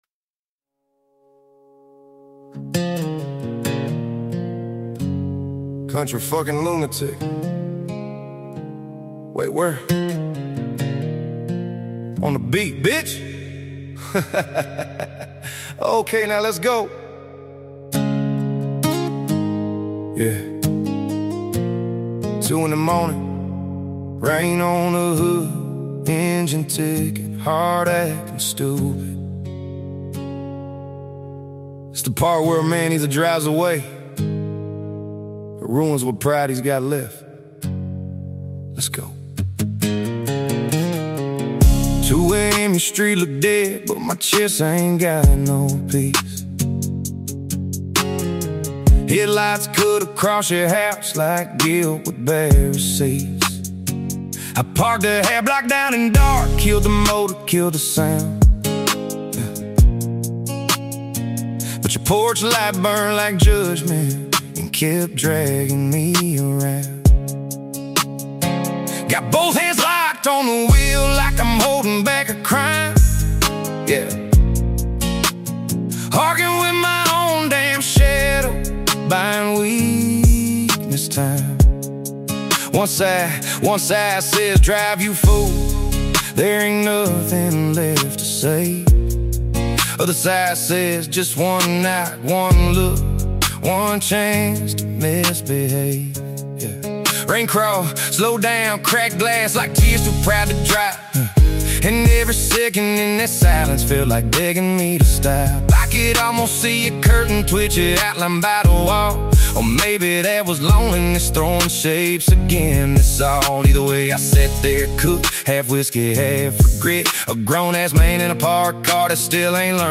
It delivers a sound that stays consistent throughout.